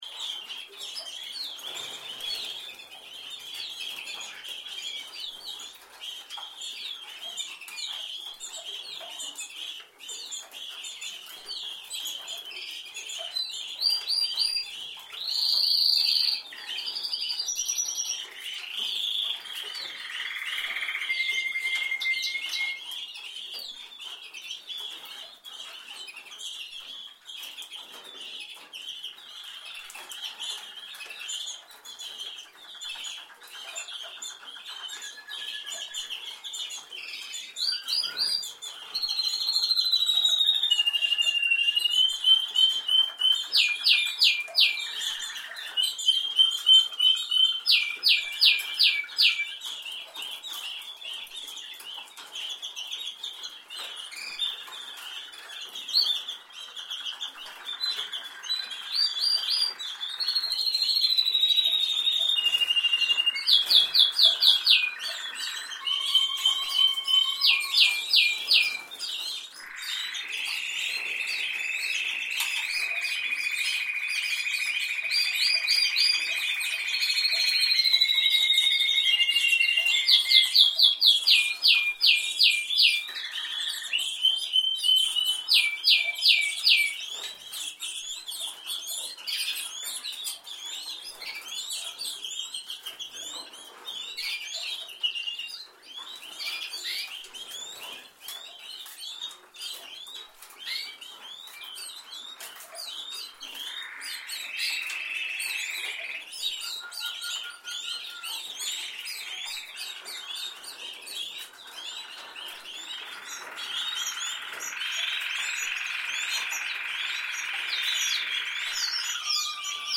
Canary Singing ringtone - free for your phone.